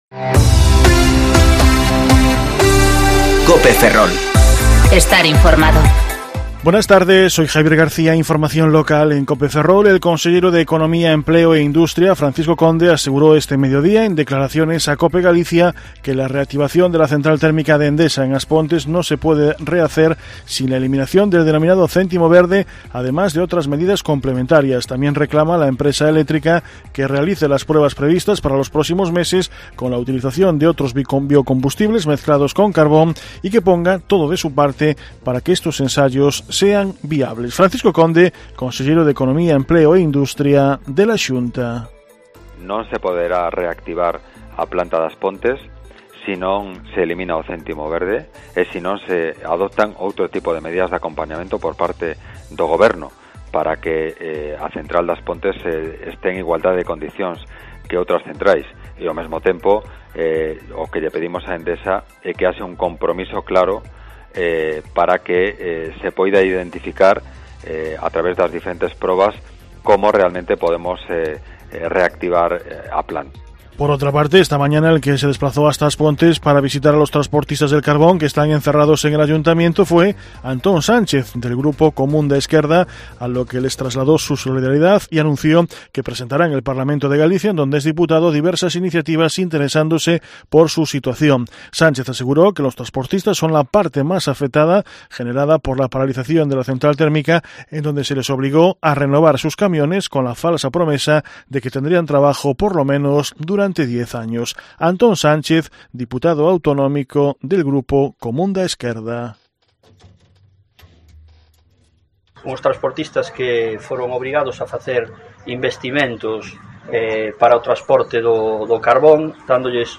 Informativo Mediodía COPE Ferrol - 10/1/2020 (De 14,20 a 14,30 horas)